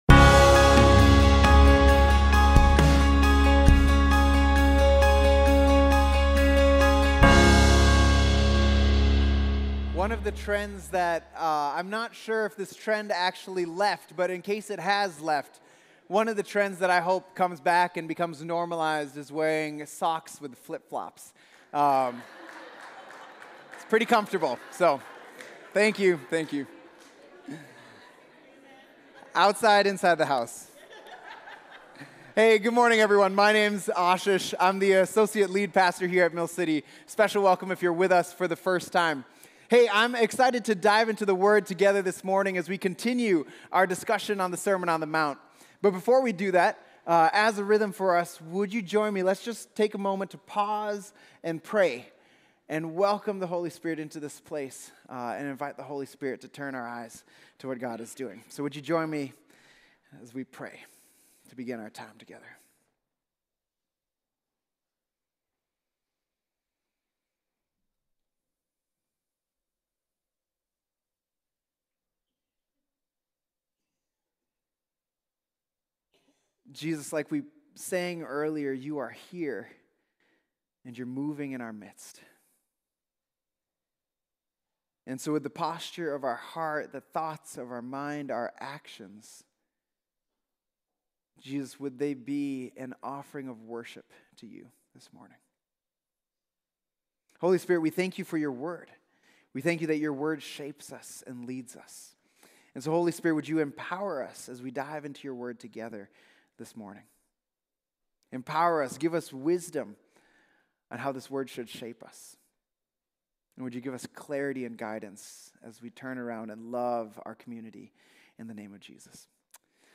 Mill City Church Sermons The Road to Life: Treasures in Heaven Jan 14 2025 | 00:35:19 1x 00:00 / 00:35:19 Subscribe Share RSS Feed Share Link Embed